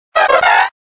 Fichier:Cri 0313 DP.ogg